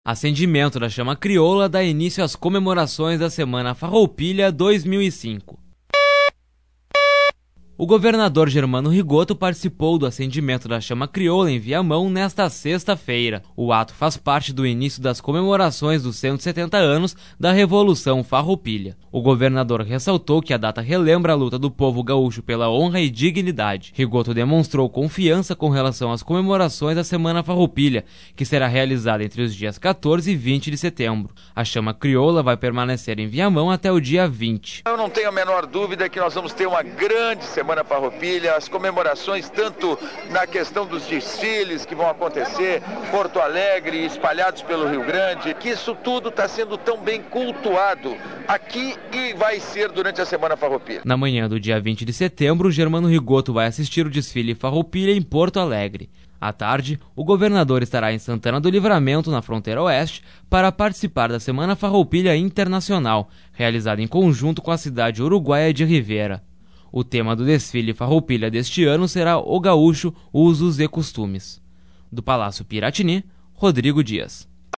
O governador GERMANO RIGOTTO participou do acendimento da Chama Crioula, em Viamão, nesta 6ª feira. O ato faz parte do início das comemorações dos 170 anos da Revolução Farroupilha.